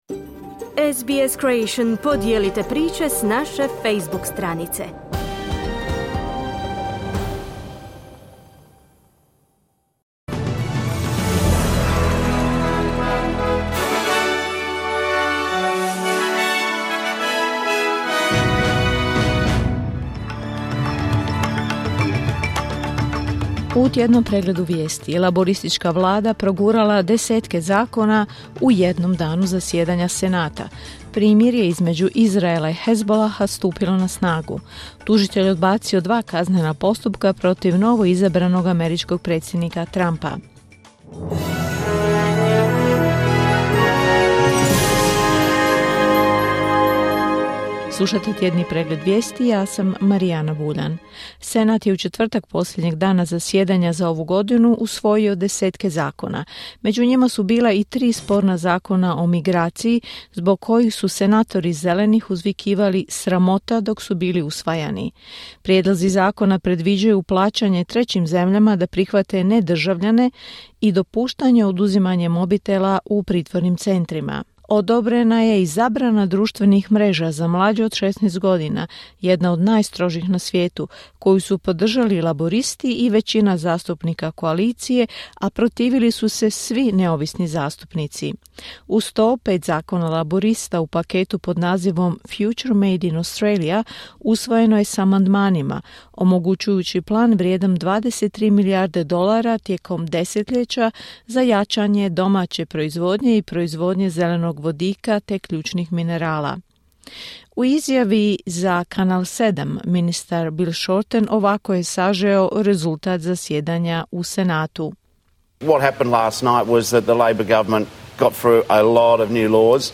Tjedni pregled vijesti, 29.11.2024.
Vijesti radija SBS.